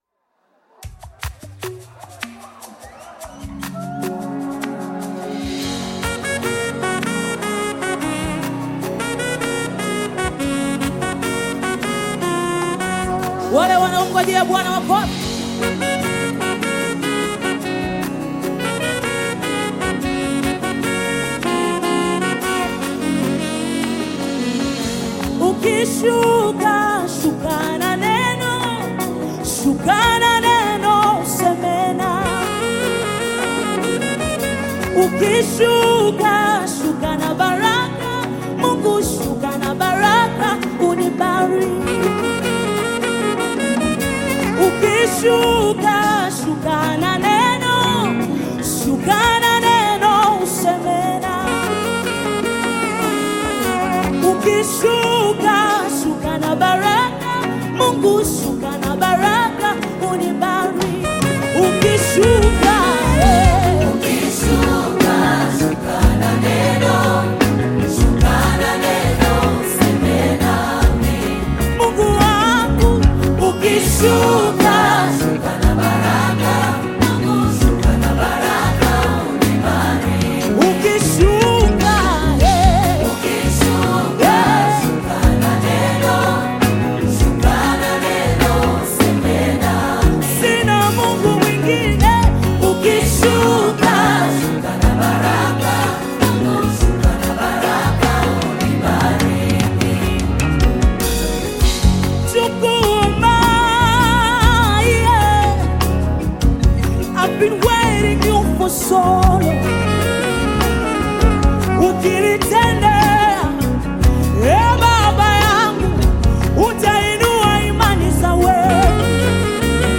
Gospel music track
Tanzanian gospel group